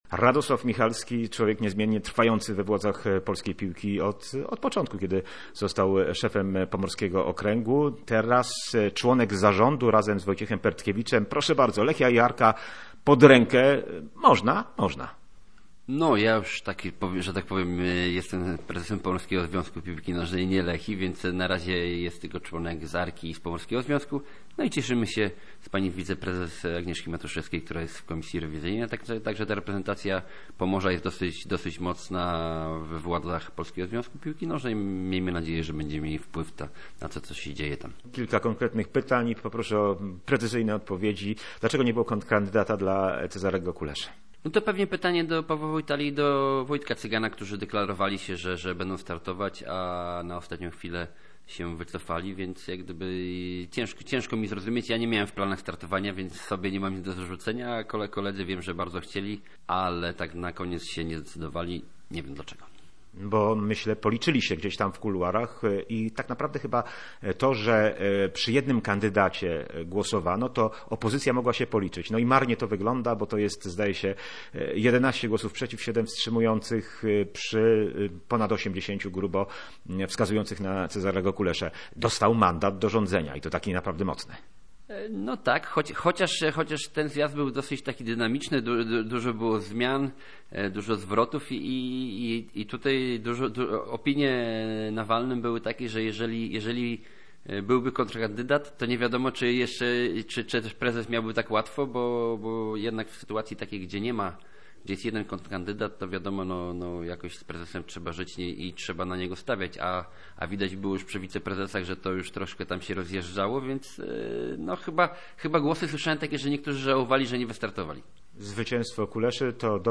w rozmowie z Radiem Gdańsk